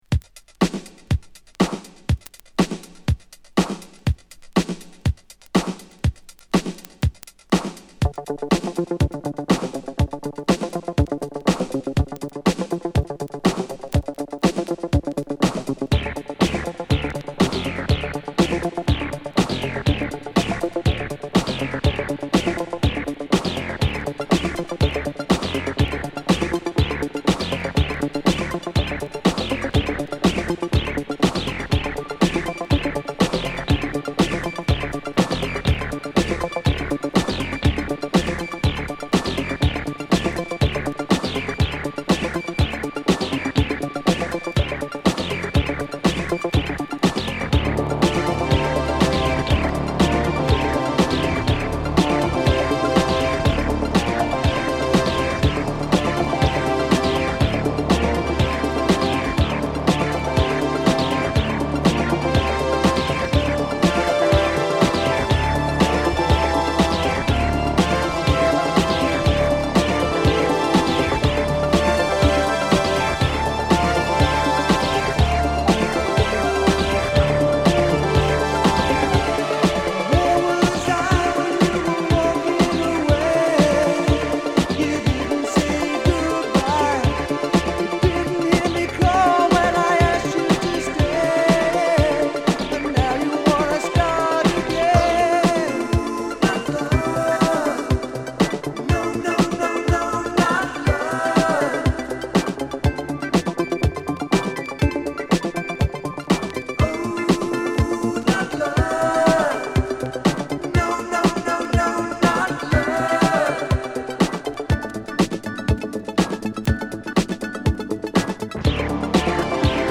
Home > Italo Disco